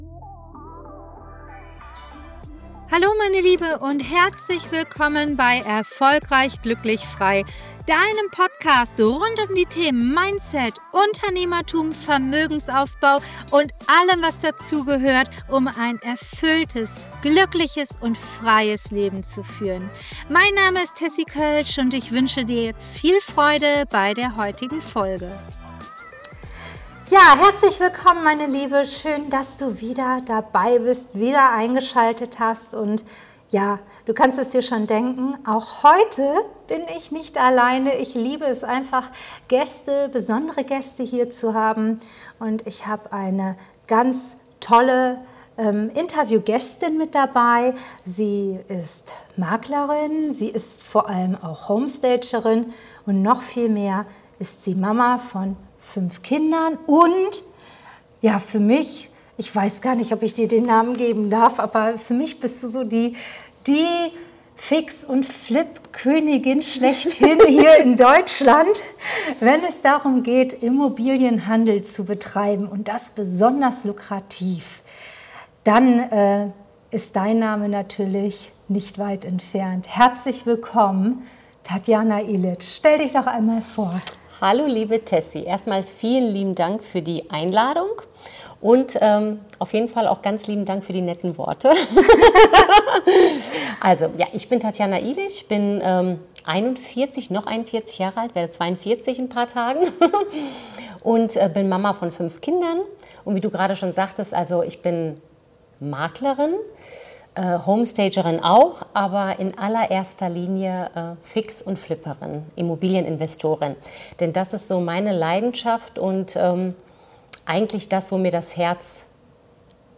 #9 Fix & Flip als Erfolgsmodell für Frauen- Interview